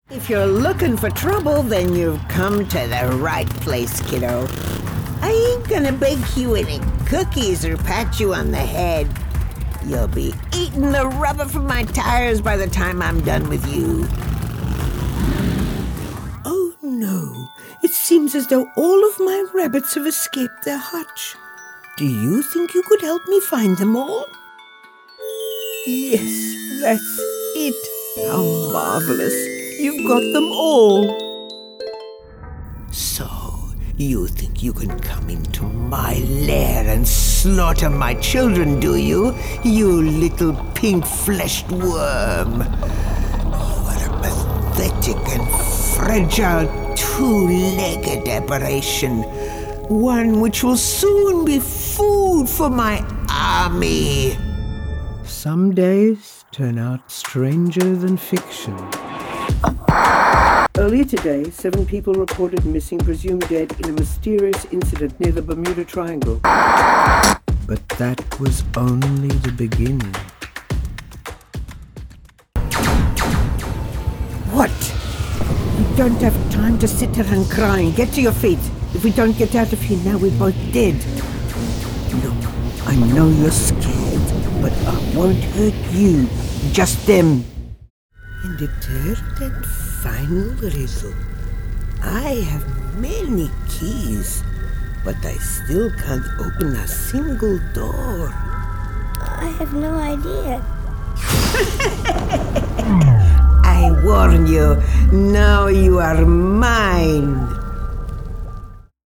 Female
English (Australian)
My voice overs are confident, warm, conversational, expressive, engaging, versatile and clear.
Video Games
Six video gaming characters with different accents and different personalities.